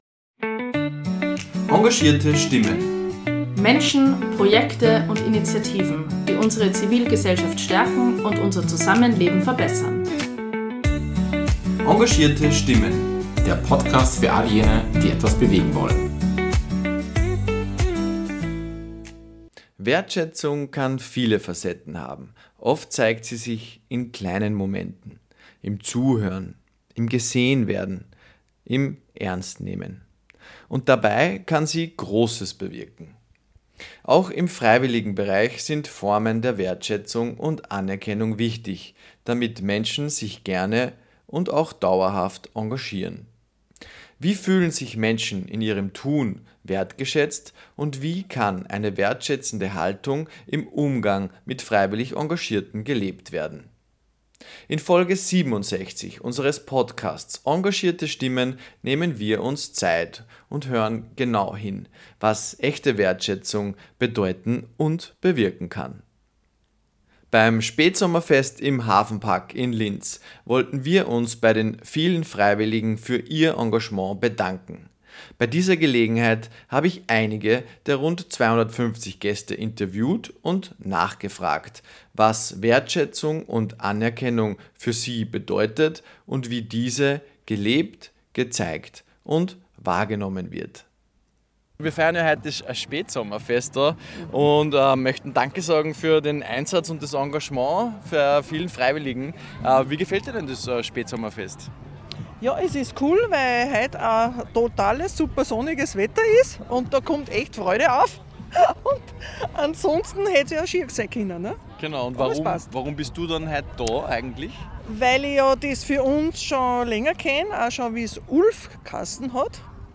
In Folge 67 hören wir genau hin: Beim Spätsommerfest im Hafenpark Linz haben wir uns bei den vielen freiwillig Engagierten bedankt. Dabei haben wir einige der rund 250 Gäste vor Ort interviewt und sie gefragt, was Wertschätzung für sie bedeutet – wie sie erlebt, gezeigt und wahrgenommen wird.